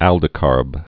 (ăldĭ-kärb)